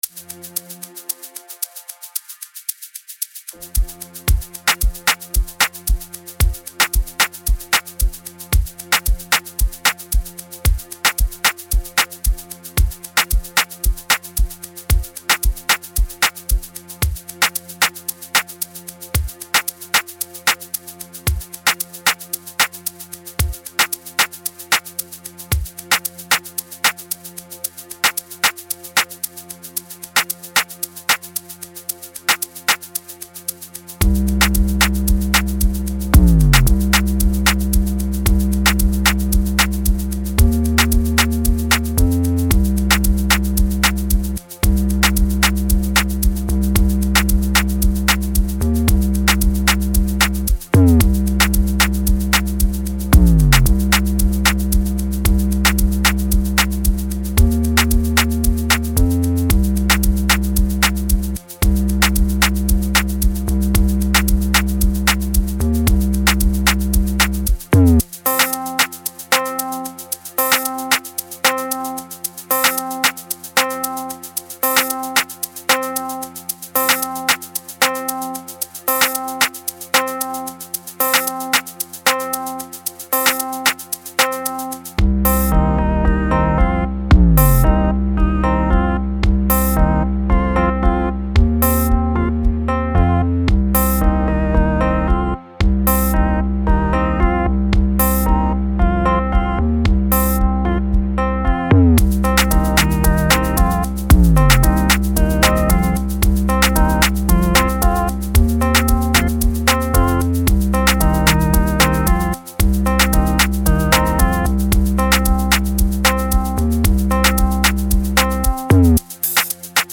06:43 Genre : Amapiano Size